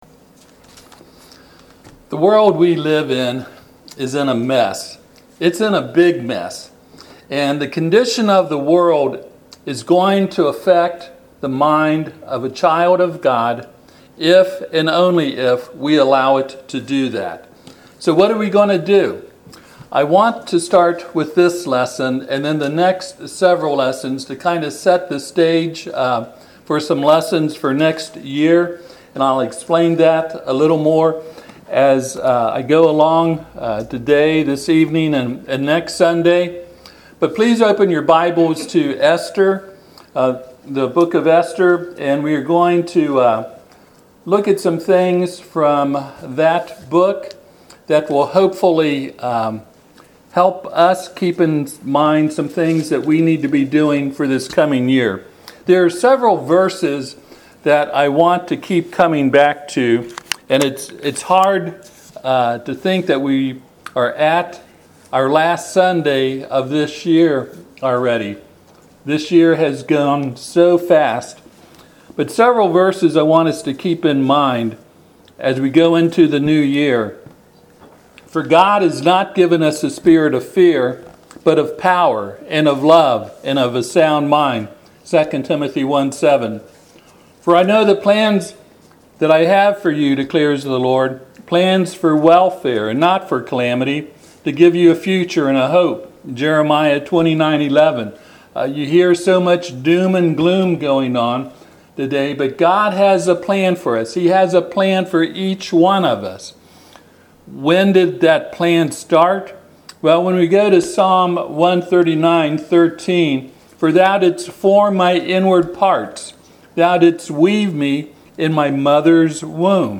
Esther 4:14 Service Type: Sunday AM The world we live in is in a mess.